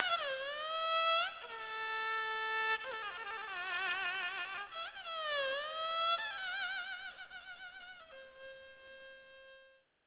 Dan Nhi or Dan Co A fiddle played with a bow, having 2 strings tuned at intervals of a fifth, an unfretted fingerboard, and a pipe-bowl-shaped sound-box.